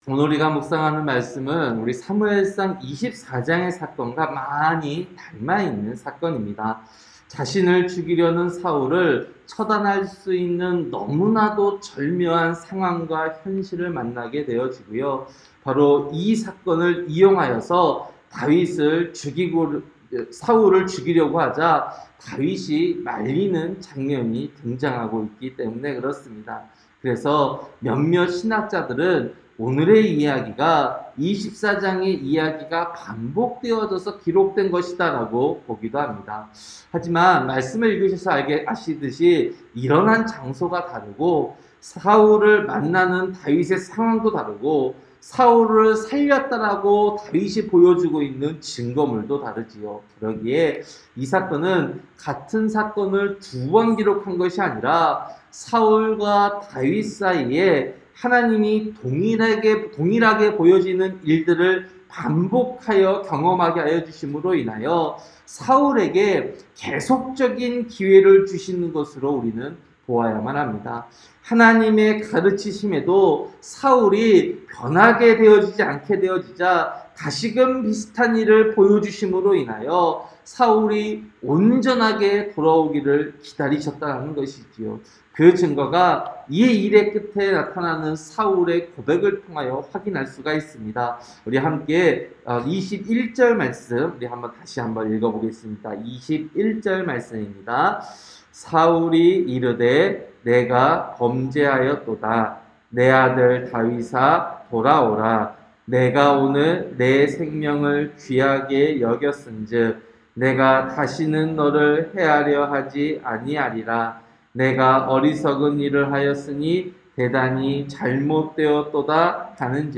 새벽설교-사무엘상 26장